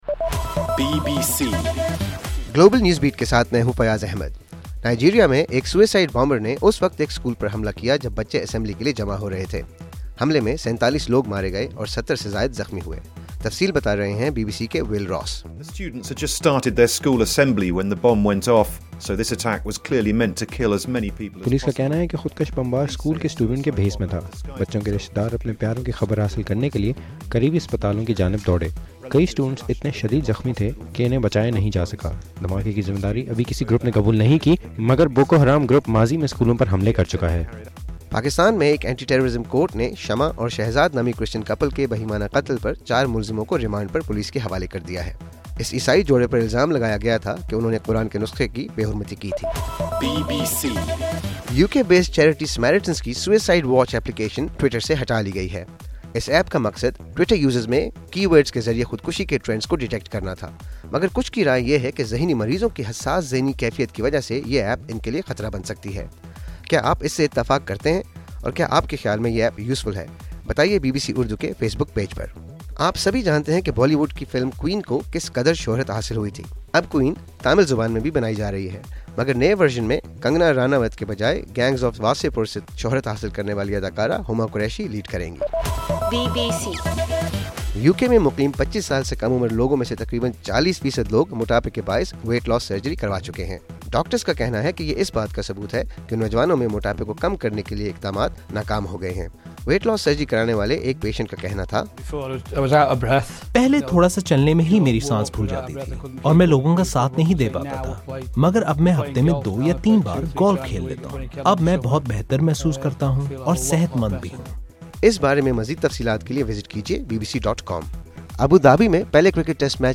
نومبر 10 : رات 11 بجے کا گلوبل نیوز بیٹ بُلیٹن